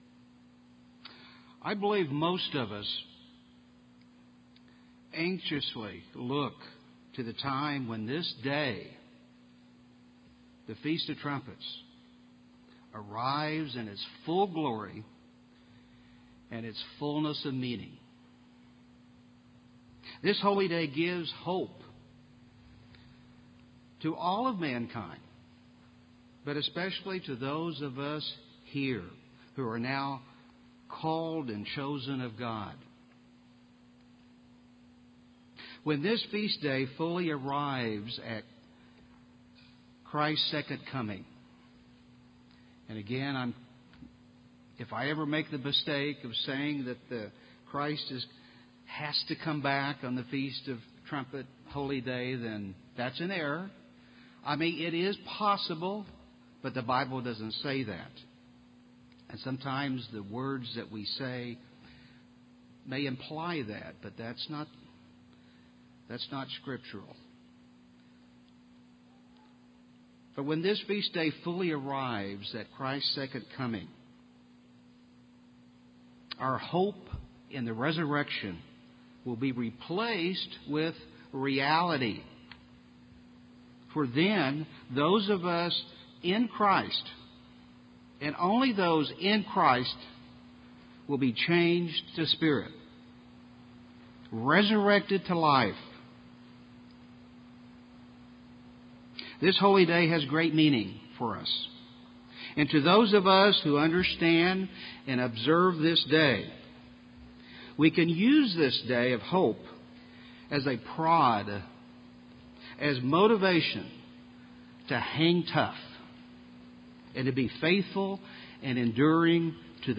Given in Tulsa, OK
UCG Sermon Studying the bible?